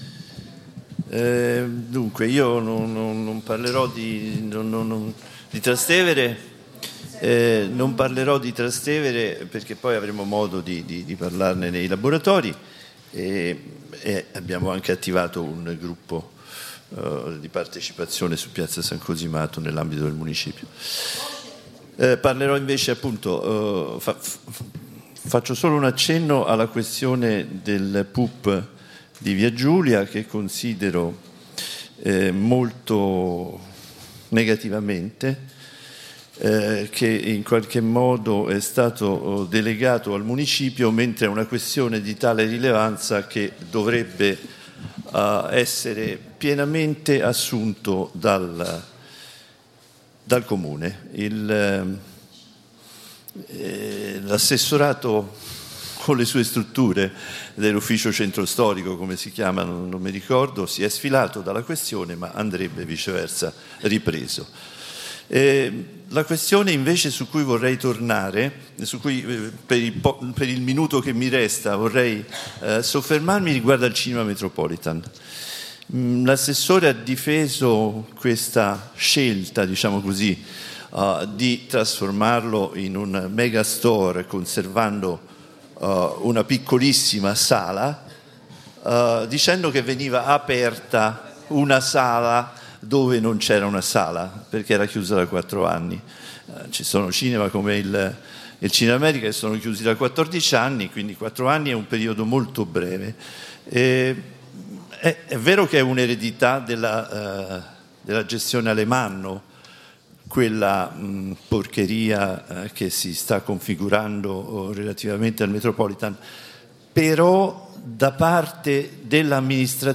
Registrazione integrale dell'incontro svoltosi il 13 ottobre 2014 presso la Casa Internazionale delle Donne, in Via della Lungara, 19.